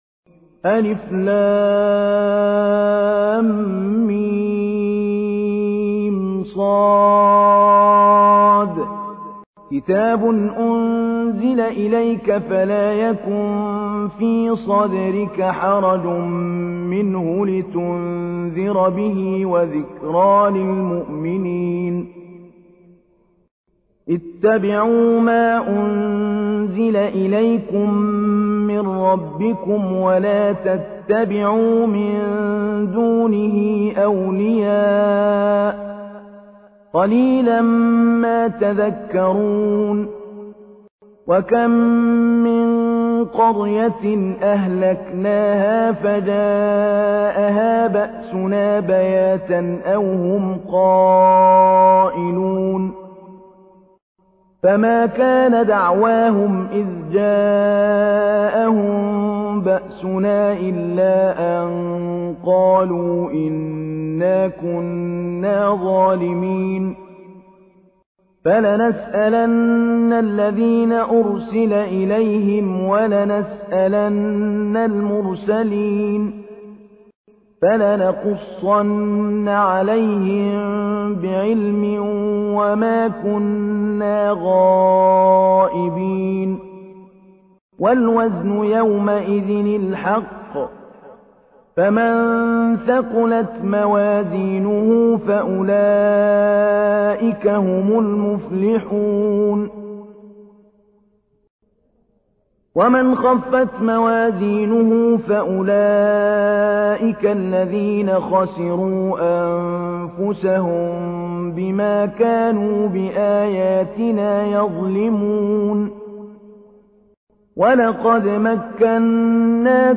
تحميل : 7. سورة الأعراف / القارئ محمود علي البنا / القرآن الكريم / موقع يا حسين